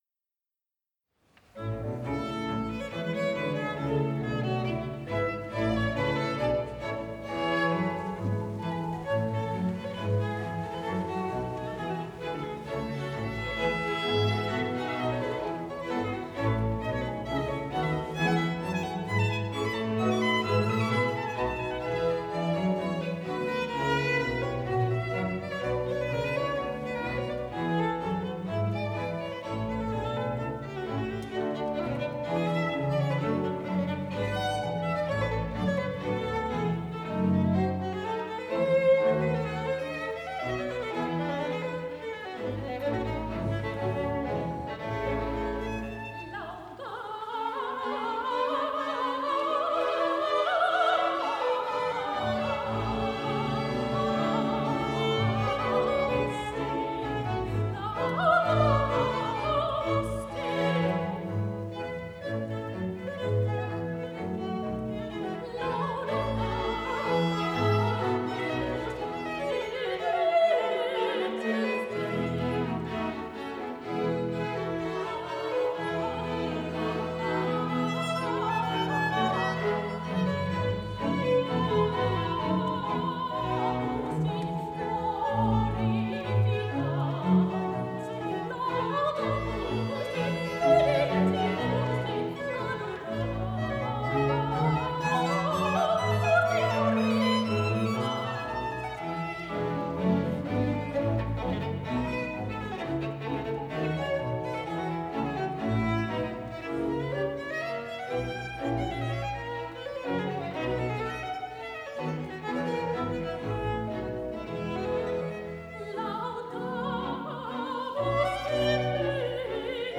Sopran
Laudamus Te J.S.Bach - Messe in h-Moll (BWV 232) Gewandhausorchester Leitung